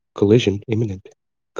collision-imminent.wav